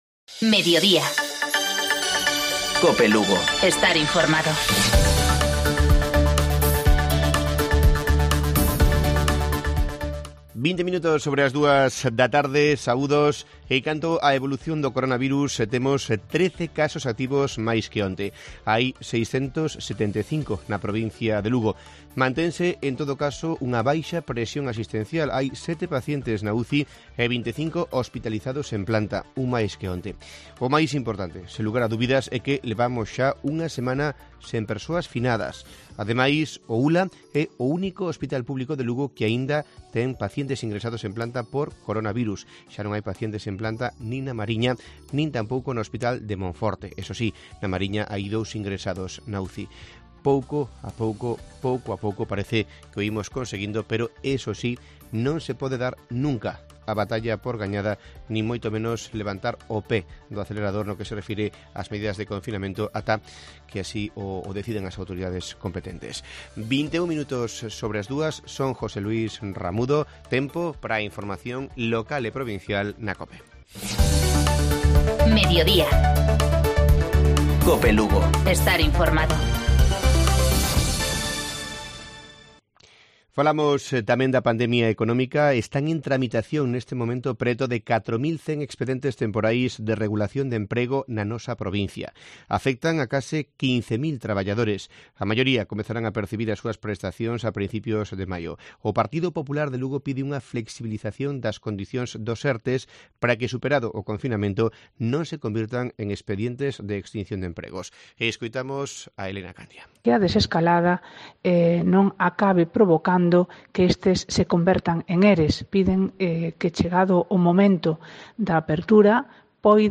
Informativo Mediodía Cope. Martes, 28 de abril. 14:20-14-30 horas.